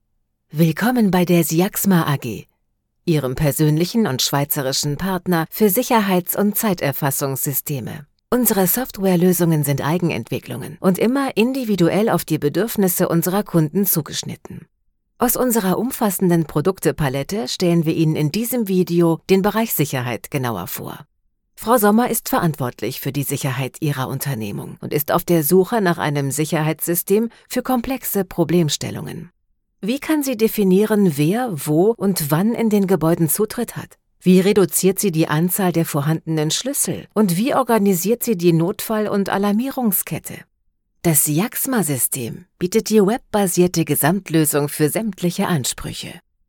Profundo, Natural, Versátil, Cálida, Empresarial
E-learning